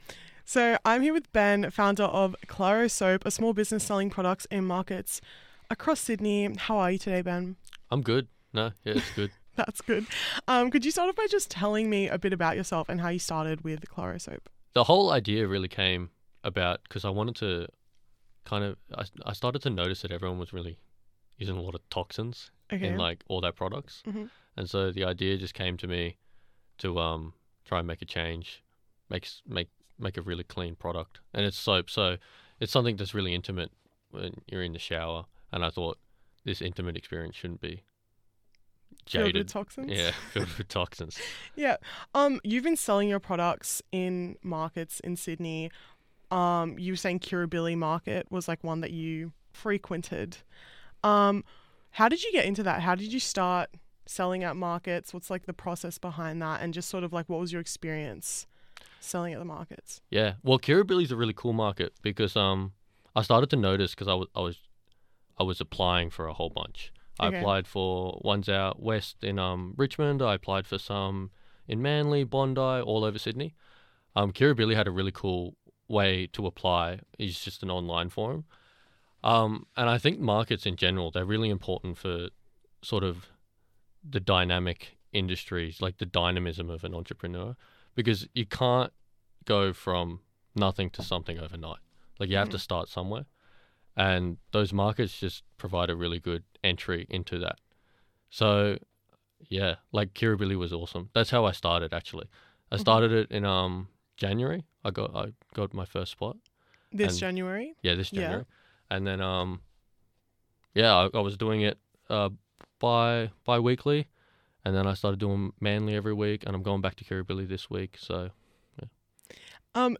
final-Kirrribilli-Interview.mp3